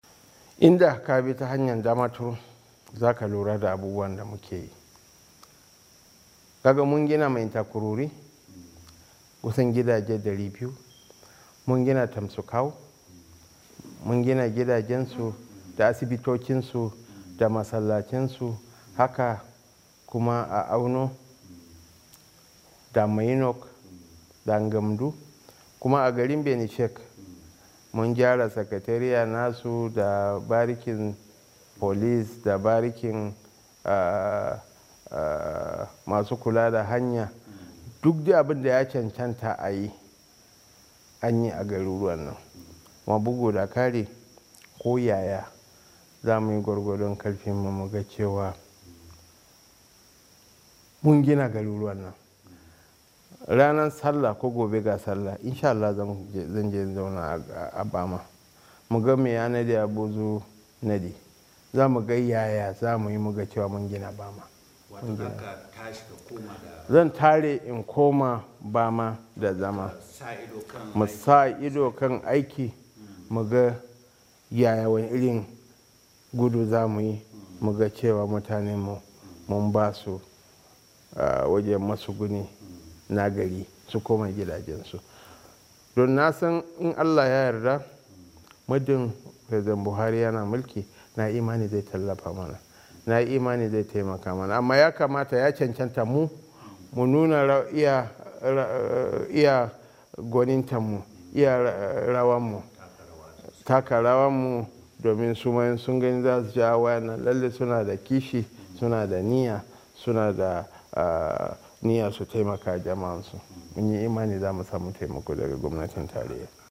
A zantawar da yayi da Muryar Amurka gwaman jihar Borno Kashim Shettima ya bayyana irin ayyukan sake gina wasu garuruwa da suka yi, kuma zasu cigaba da yi har duk mutanen dake gudun hijira sun samu sun koma matsuguninsu.